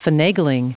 Prononciation du mot finagling en anglais (fichier audio)
Prononciation du mot : finagling